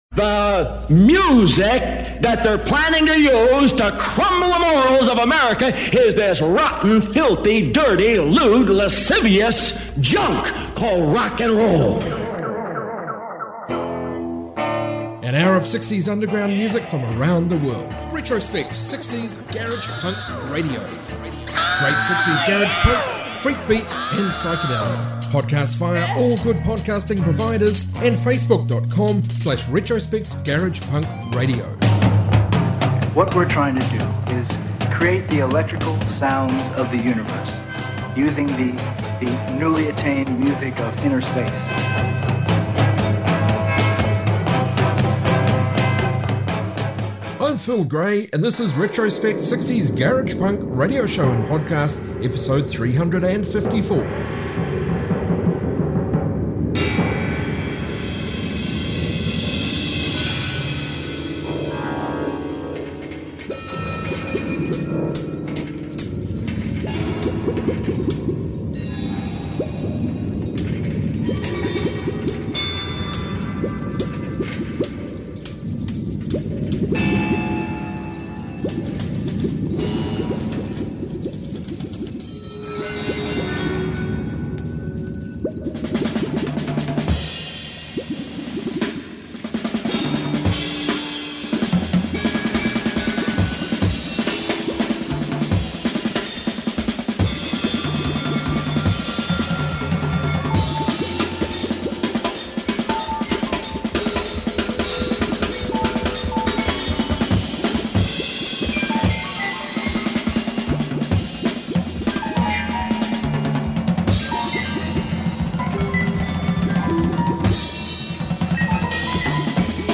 60s global garage rock